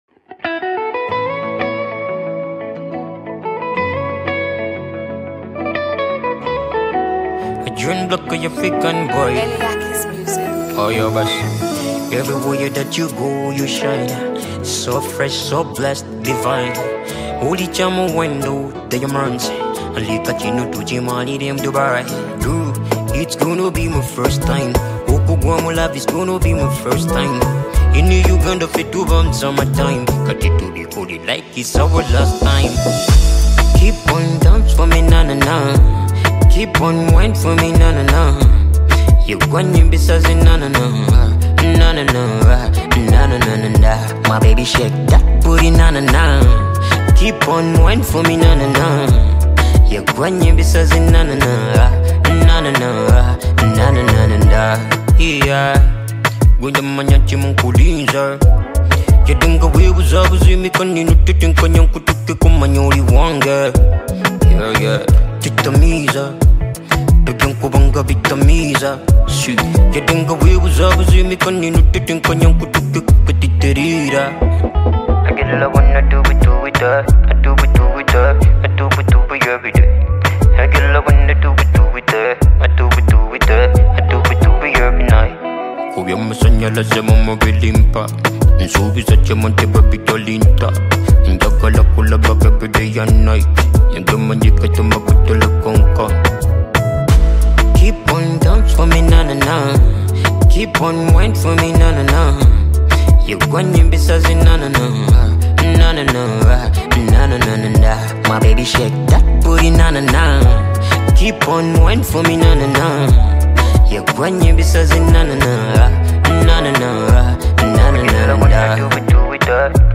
Genre: RNB